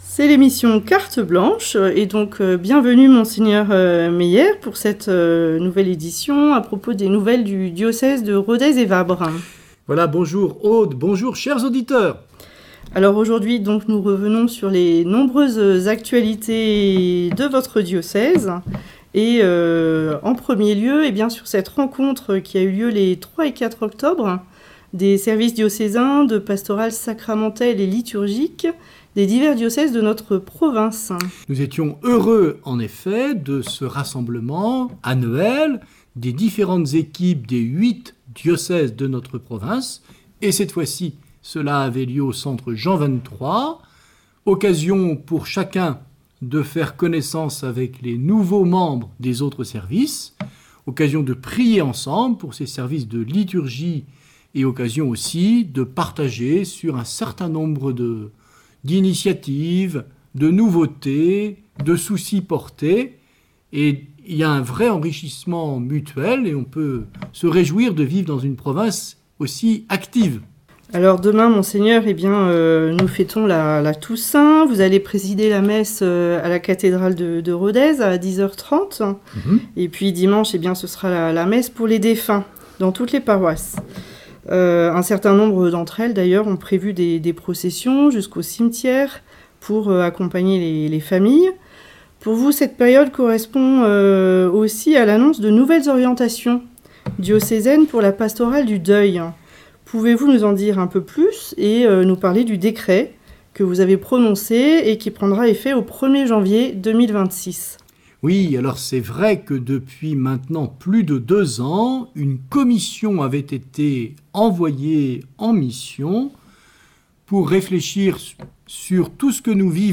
Une émission présentée par Mgr Luc Meyer Evêque du diocèse de Rodez et Vabres